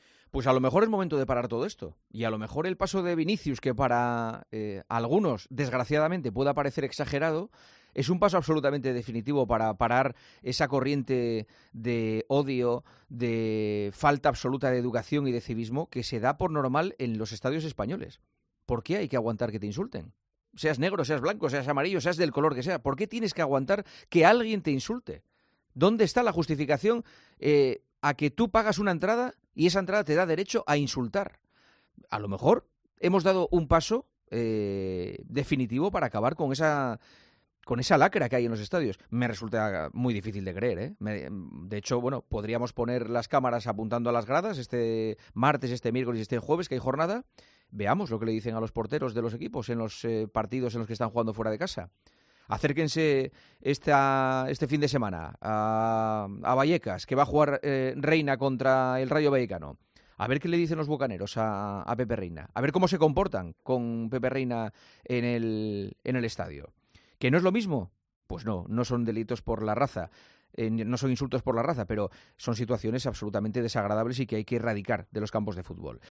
AUDIO: El presentador de 'El Partidazo de COPE' reflexiona sobre lo que deja el episodio racista que sufrió Vinicius Júnior en Valencia y todas las reacciones...